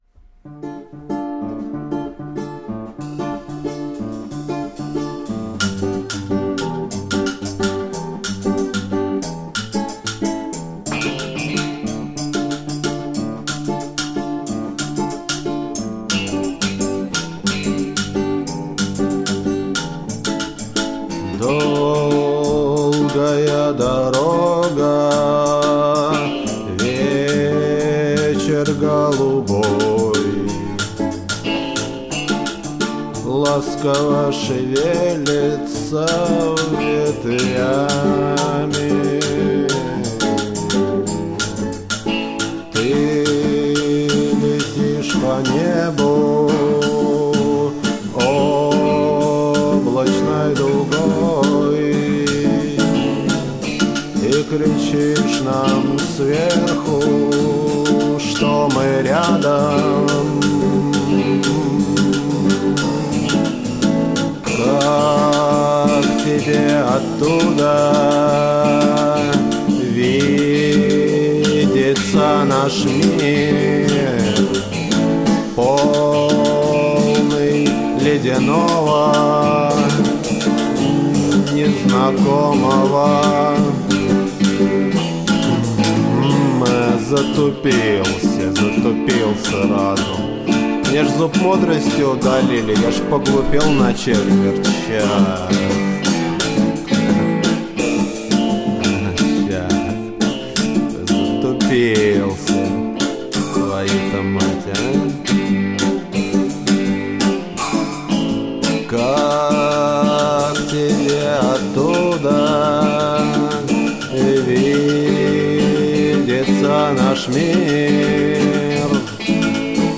вокал.
клавиши, гитара.
перкуссия, ударные, бас, программирование драм-машины.